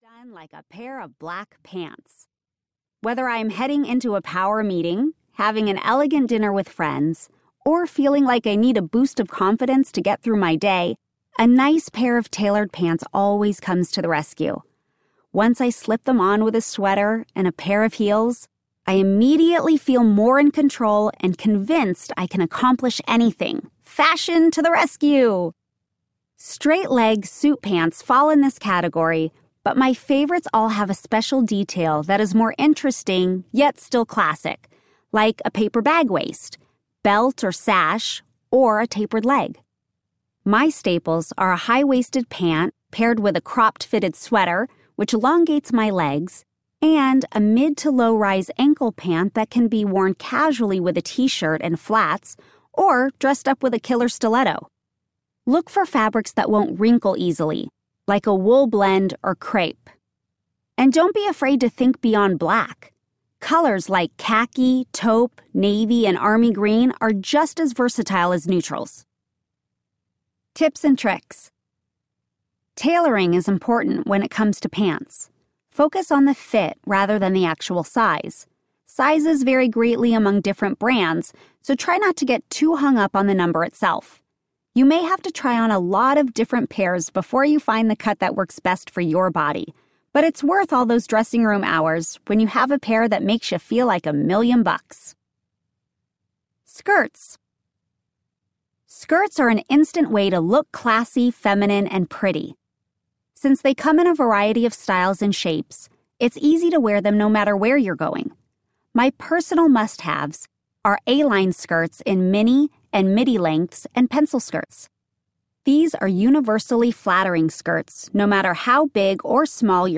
Staying Stylish Audiobook
Narrator
Candace Cameron Bure
2.2 Hrs. – Unabridged